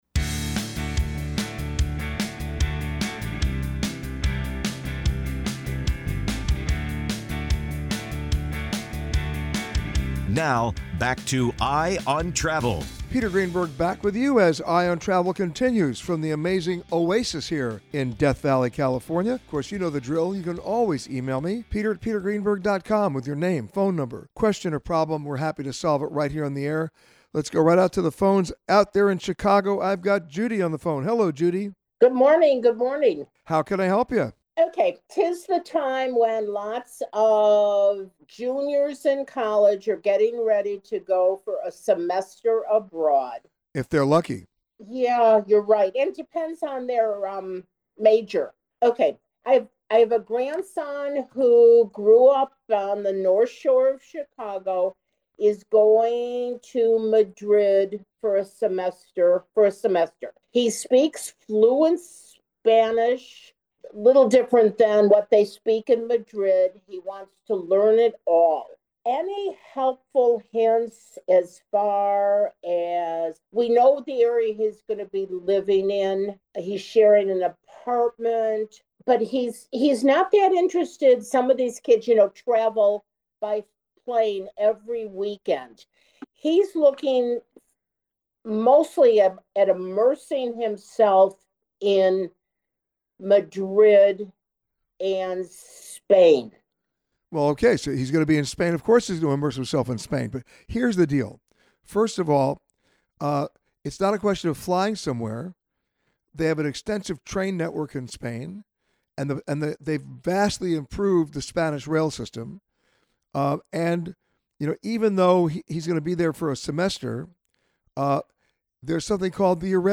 This week, Peter answered your questions from The Oasis at Death Valley in Death Valley, California.